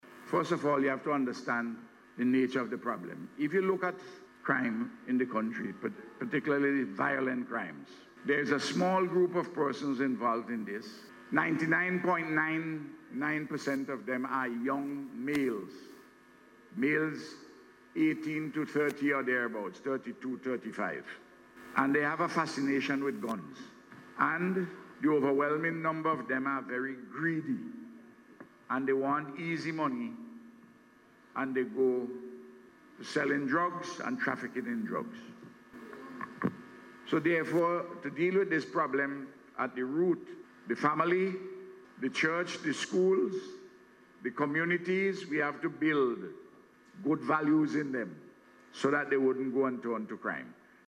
So says Prime Minister and Minister of National Security, Dr. Ralph Gonsalves as he responded to a question from a student about the spike in crime during a recent school tour.